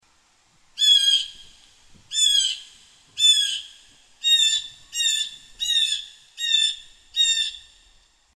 blueJay.mp3